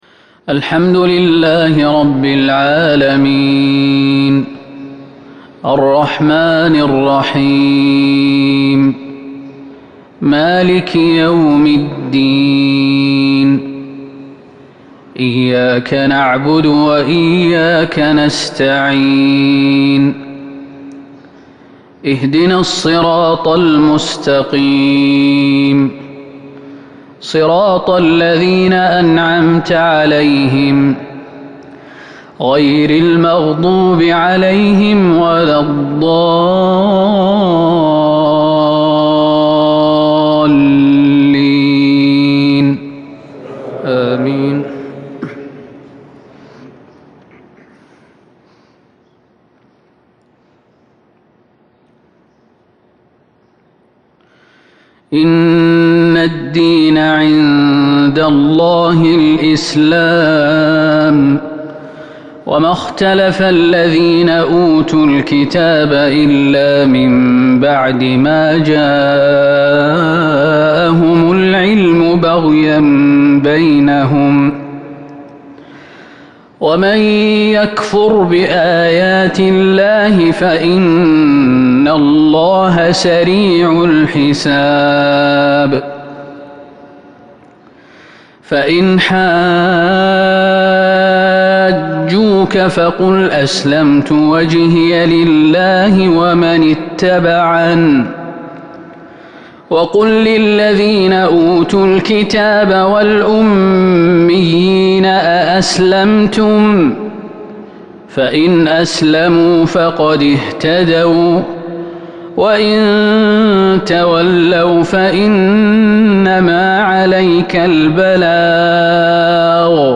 صلاة فجر الأربعاء من سورة آل عمران ٢١ محرم ١٤٤٢هـ fajr prayer from surah Al-Imran 9/9/2020 > 1442 🕌 > الفروض - تلاوات الحرمين